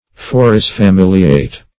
Forisfamiliate \Fo`ris*fa*mil"i*ate\, v. i. (Law)